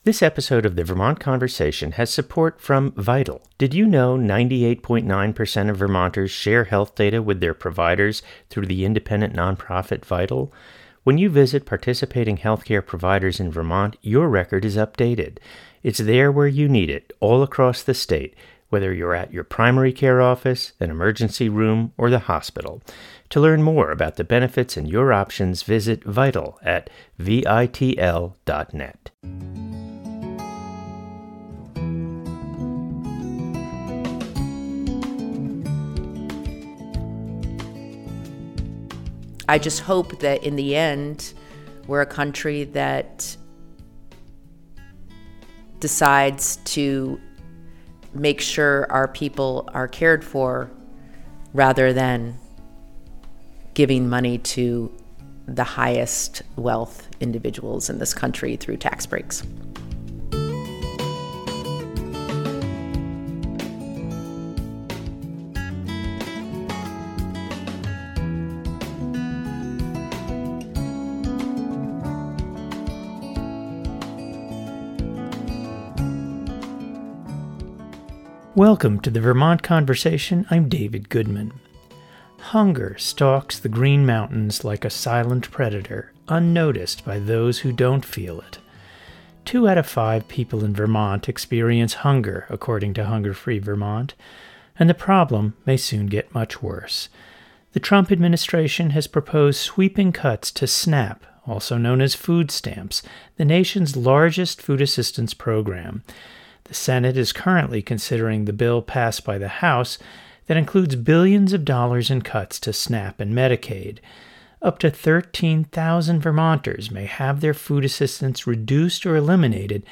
in-depth interviews